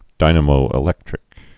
(dīnə-mōĭ-lĕktrĭk) also dy·na·mo·e·lec·tri·cal (-trĭ-kəl)